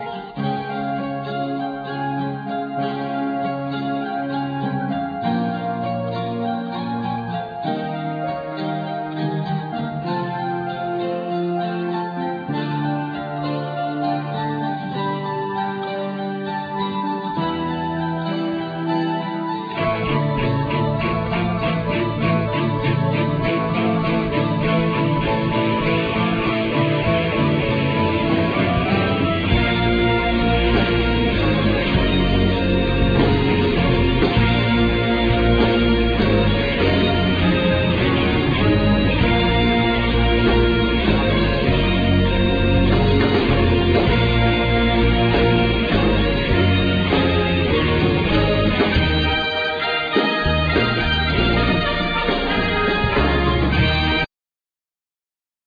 Drums
Keyboards
Vocals
Guitar
Bass
Tenor Saxophone
Backing vocals
Timpani